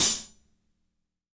-16  HAT 3-L.wav